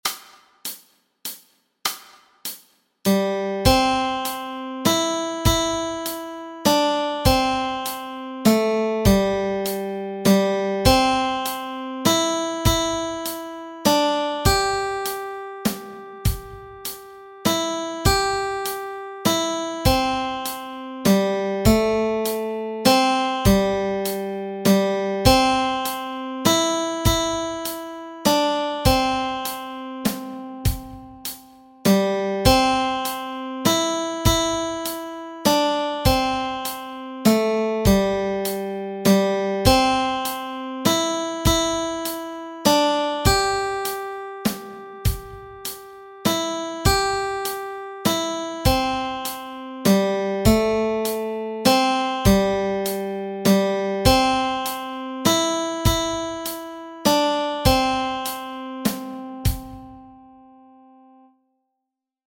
Melody Track